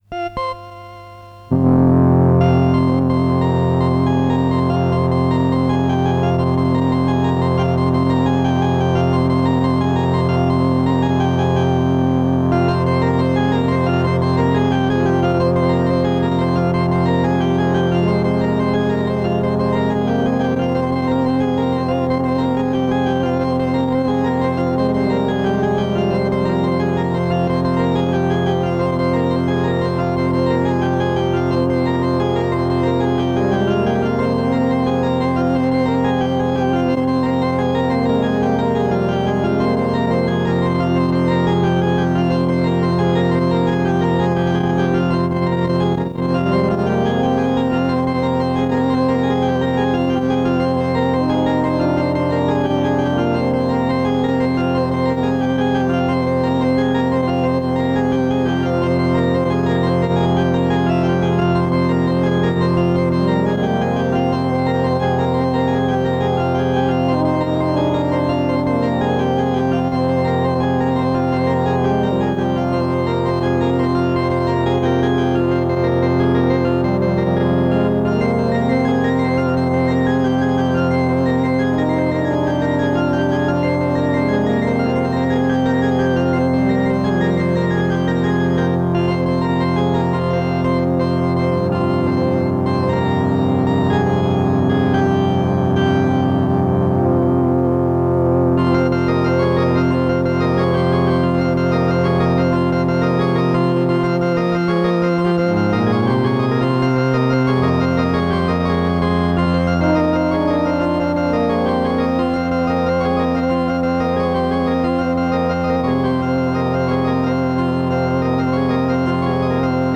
Synthstuff Casio CK500